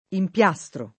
vai all'elenco alfabetico delle voci ingrandisci il carattere 100% rimpicciolisci il carattere stampa invia tramite posta elettronica codividi su Facebook impiastro [ imp L#S tro ] o empiastro [ emp L#S tro ] s. m.